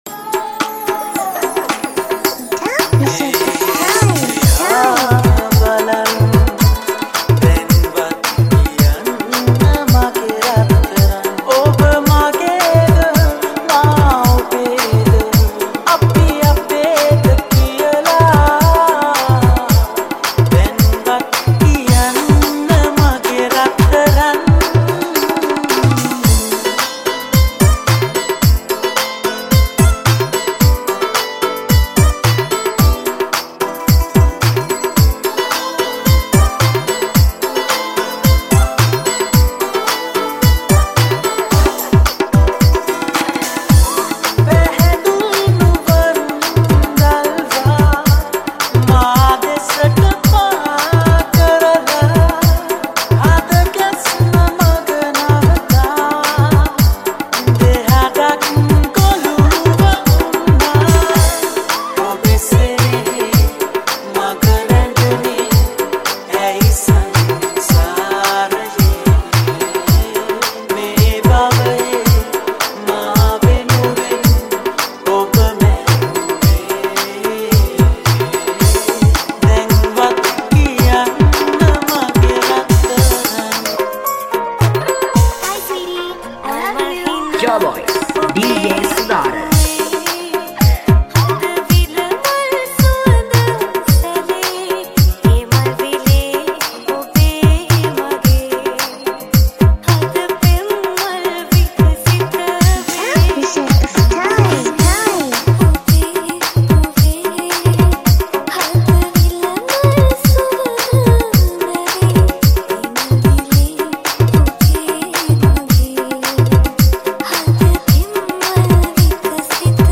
High quality Sri Lankan remix MP3 (3.8).